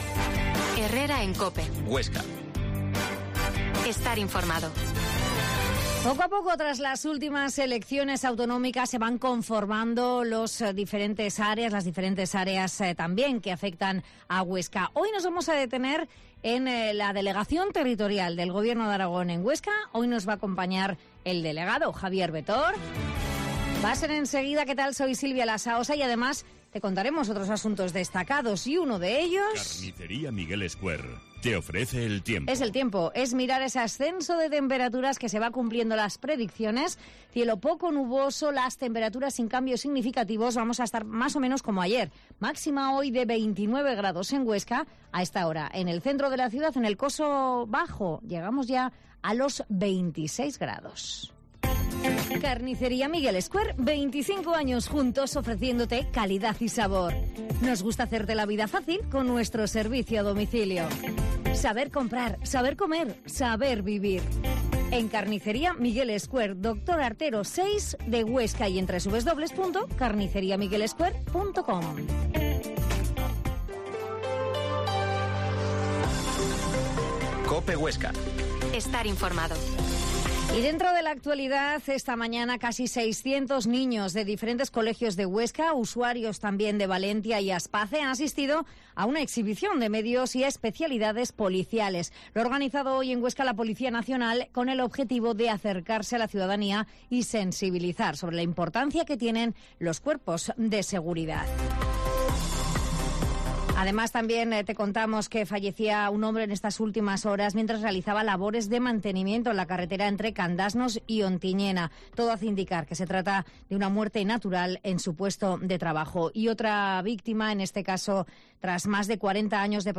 Herrera en COPE Huesca 12.50h Entrevista al delegado territorial de la DGA en Huesca, Javier Betorz